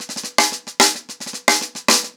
TheQuest-110BPM.3.wav